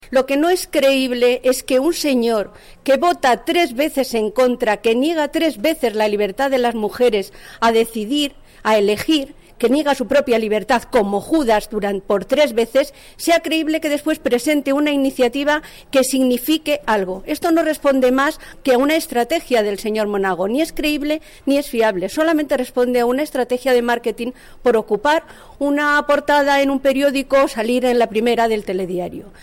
Declaraciones de Marisol Pérez sobre la reforma del aborto y la crisis que está provocando en el PP 16/01/2014